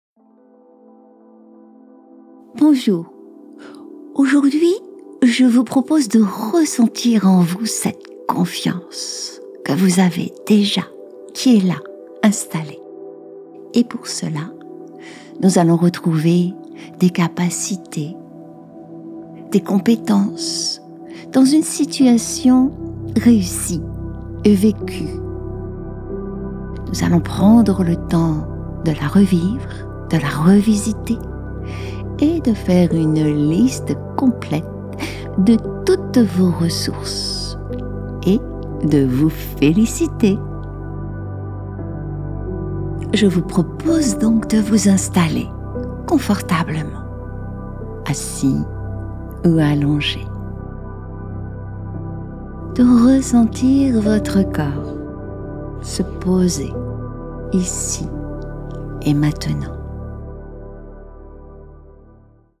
Relaxation guidée – Confiance en soi – La réussite
Cette séance de relaxation guidée peut se pratiquer aussi en journée.